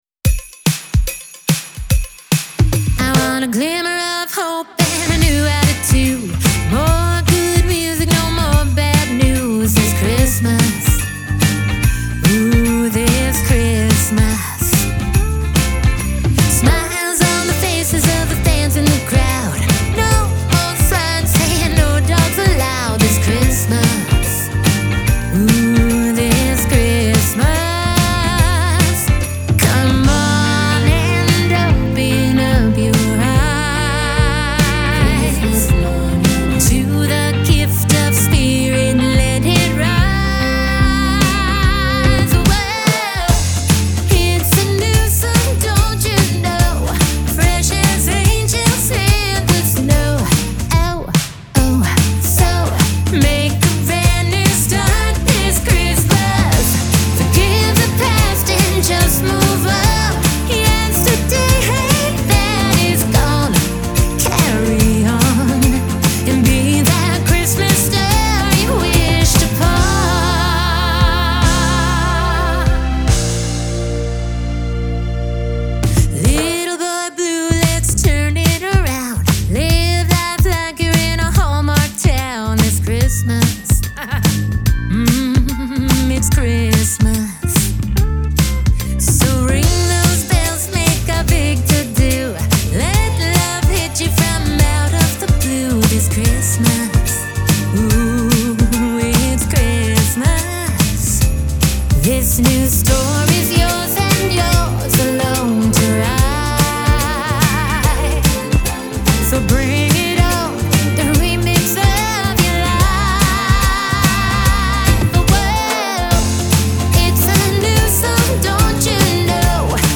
Genre : Christmas Music